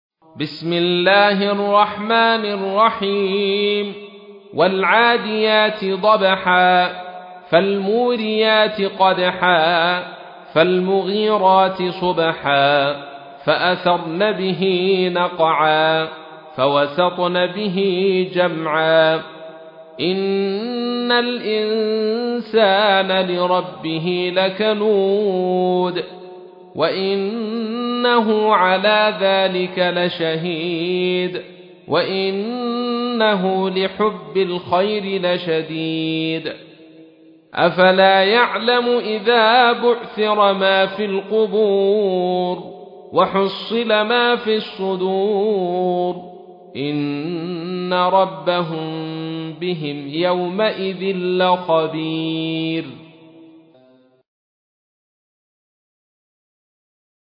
تحميل : 100. سورة العاديات / القارئ عبد الرشيد صوفي / القرآن الكريم / موقع يا حسين